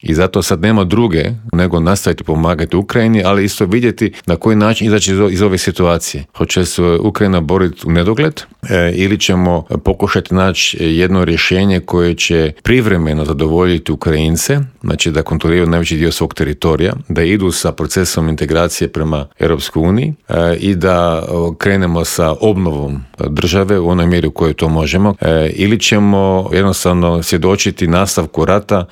ZAGREB - Dok napetosti oko širenja Europske unije i nastavka pomoći Ukrajini traju, mađarski premijer Viktor Orban riskira i pokušava svojoj državi priskrbiti sredstva koja je Europska unija zamrznula, pojašnjava u Intervjuu Media servisa bivši inoministar Miro Kovač.